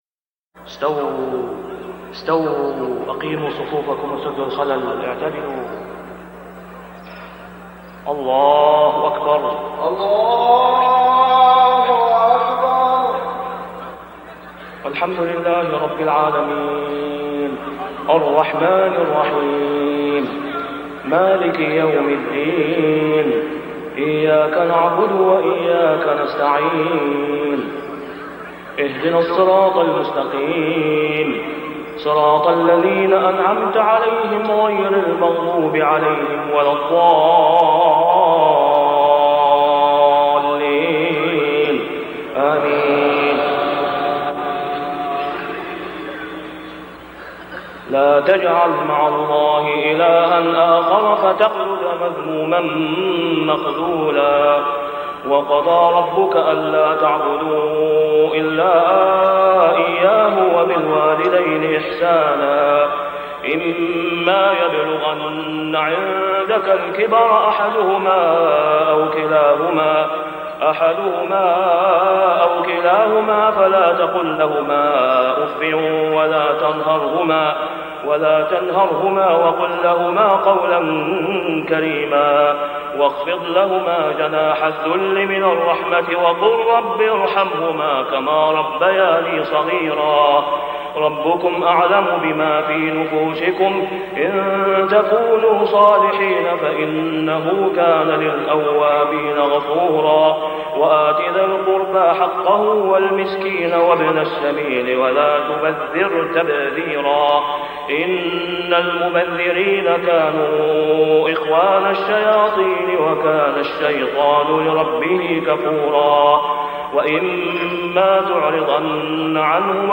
صلاة العشاء ( العام مجهول ) | ماتيسر من سورة الإسراء 22-39 | > 1425 🕋 > الفروض - تلاوات الحرمين